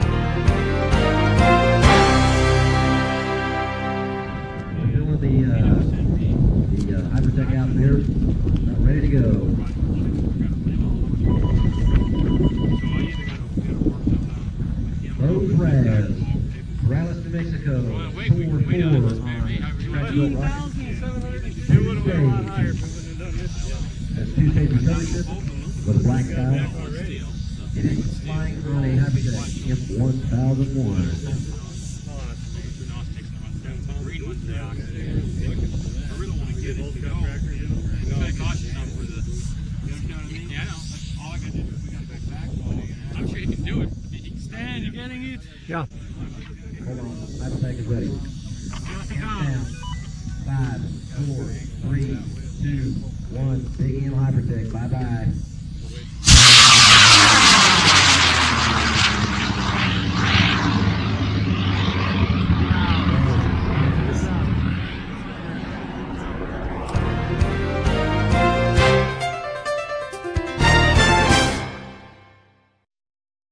See some of the cool video taken at LDRS: